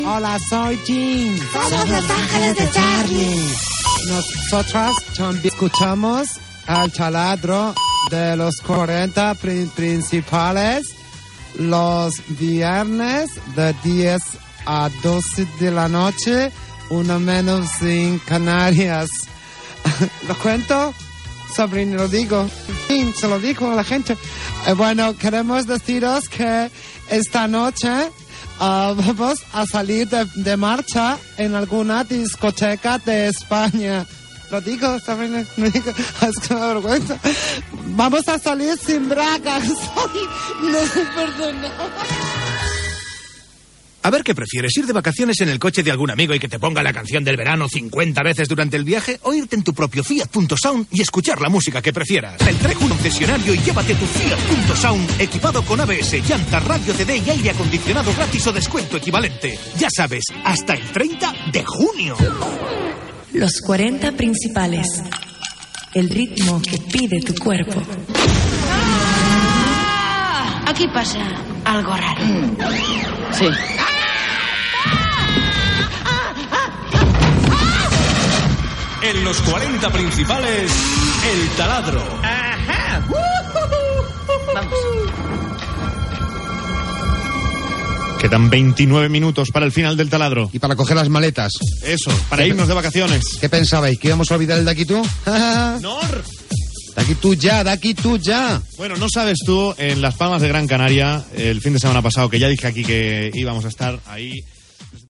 Indicatiu del programa, publicitat, indicatiu de la cadena, indicatiu del programa, l'equip del programa se'n va de vacances
FM